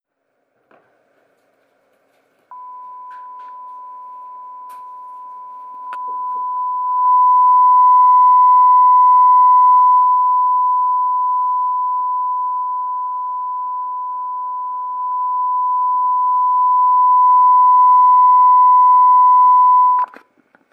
It's particularly visible in the sine wave recording that the AGC isn't adjusting the gain when the amplitude of the signal through the microphone increases/falls.
Sin1000Hz.mp3